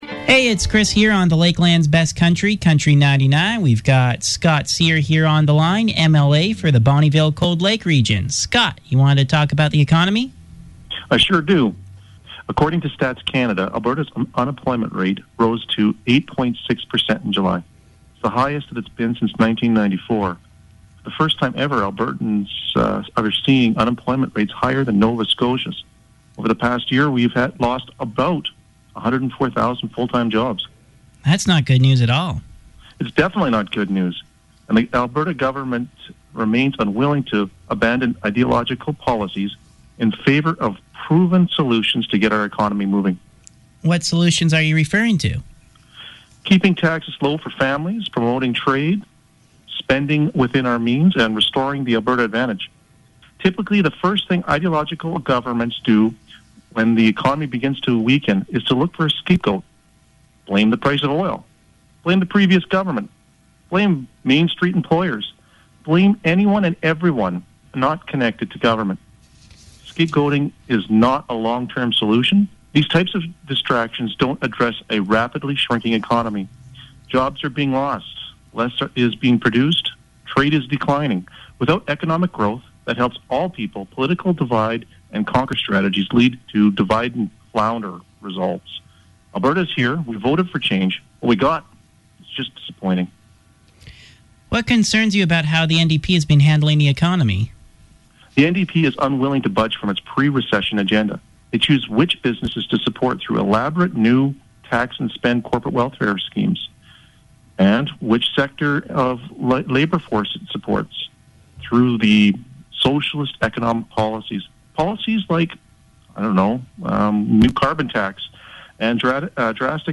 Interview With Scott Cyr